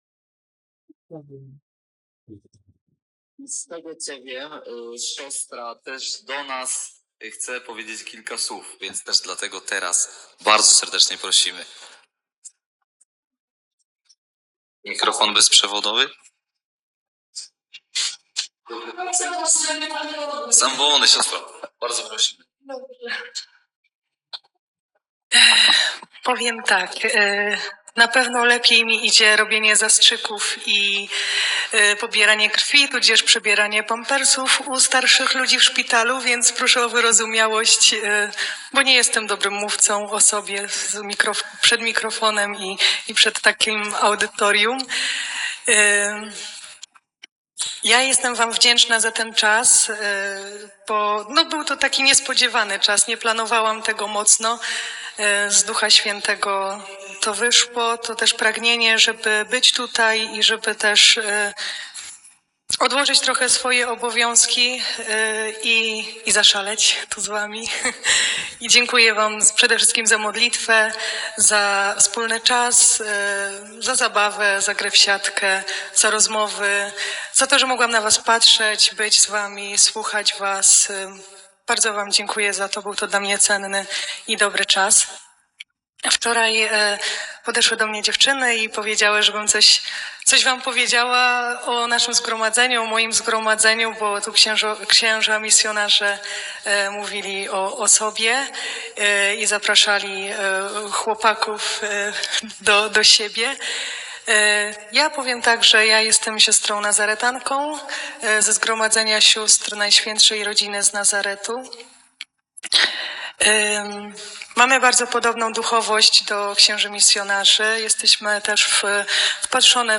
Świadectwo